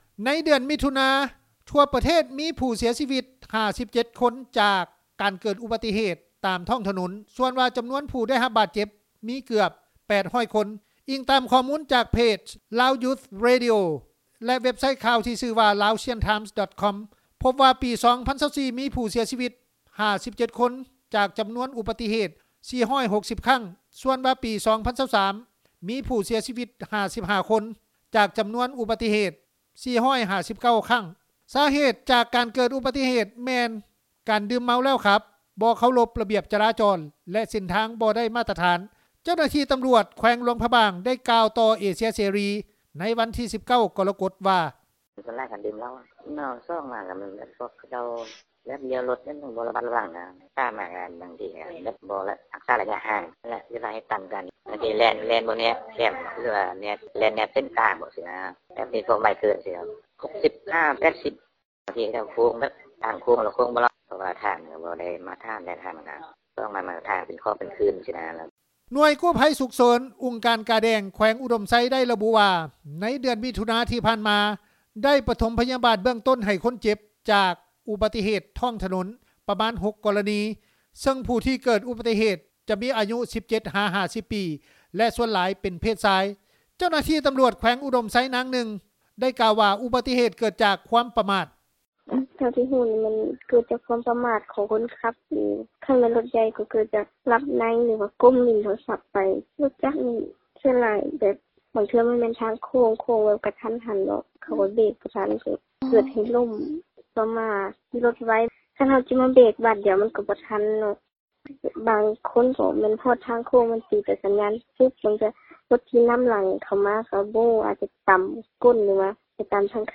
ເຈົ້າໜ້າທີ່ຕໍາຫຼວດ ແຂວງຫລວງພຣະບາງ ໄດ້ກ່າວຕໍ່ເອເຊັຽເສຣີ ໃນວັນທີ 19 ກໍລະກົດ ວ່າ:
ເຈົ້າໜ້າທີ່ີຕໍາຫຼວດ ແຂວງອຸດົມໄຊ ນາງນຶ່ງ ໄດ້ກ່າວວ່າ ອຸບັດຕິເຫດເກີດຈາກຄວາມປະໝາດ:
ຊາວນະຄອນຫລວງວຽງຈັນ ທ່ານນຶ່ງ ໄດ້ກ່າວວ່າ: